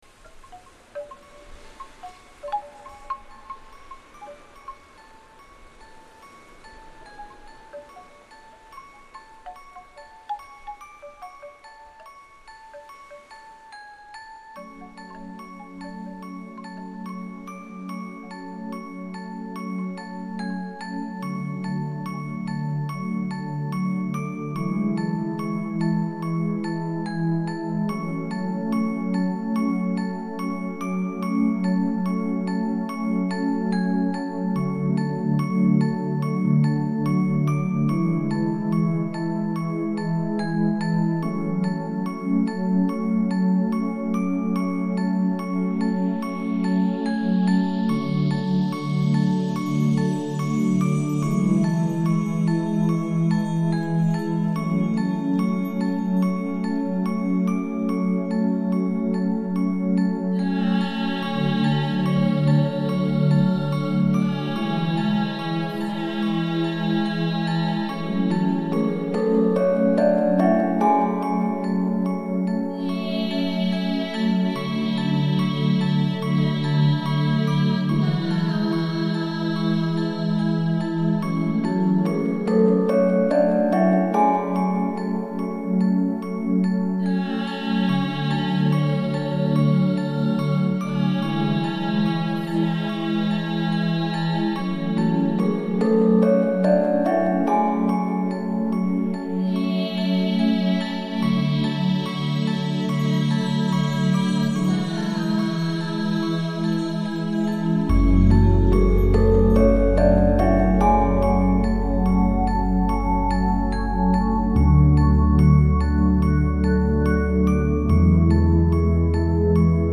登場する楽器は. バリの伝統的音楽ガムランの楽器が中心となっています。
在这张专辑中，曲调都是基于5个音阶。